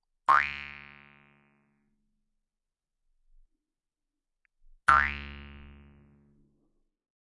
口腔竖琴第一卷 " 口腔竖琴11下限音变奏曲2
描述：口琴（通常被称为“犹太人的竖琴”）调到C＃。 用RØDENT2A录制。
Tag: 竖琴 调整Mouthharp 共振峰 仪器 传统 jewsharp 共振峰 弗利